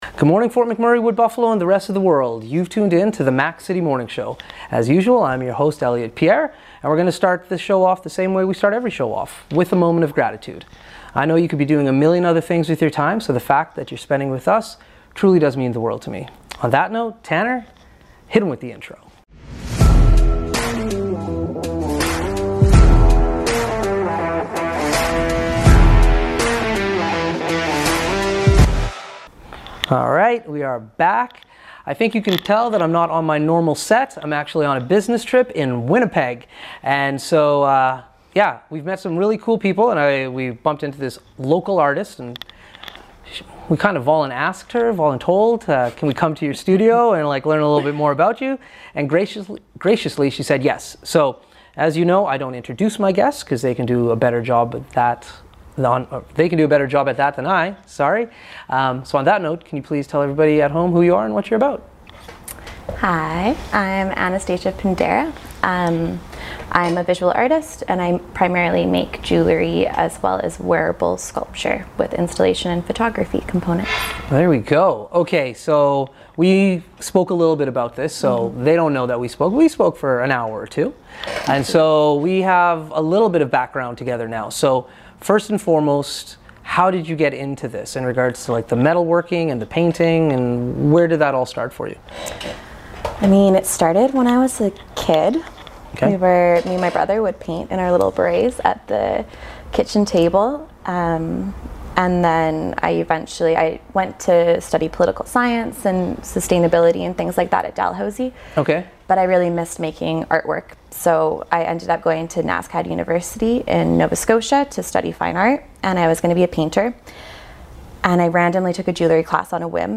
The Mac City Morning Show is still on the road!